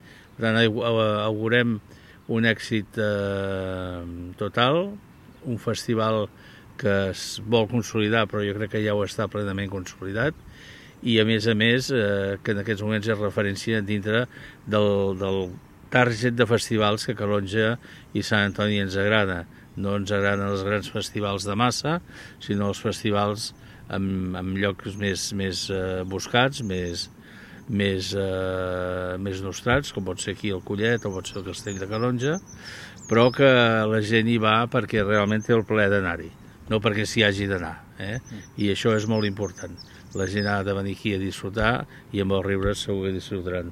L’alcalde de Calonge i Sant Antoni, Jordi Soler, ha subratllat que l’estiu al municipi “és sinònim de cultura feta a casa nostra” i que el Festival Riures es consolida com una proposta de referència.